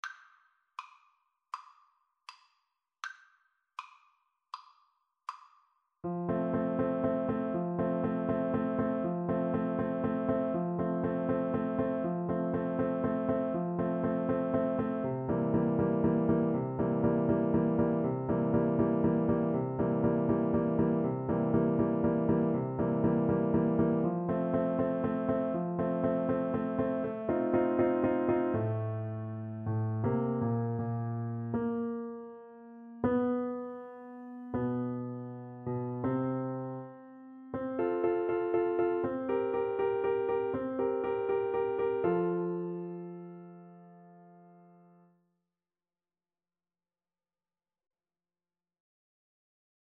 Andante
Classical (View more Classical Piano Duet Music)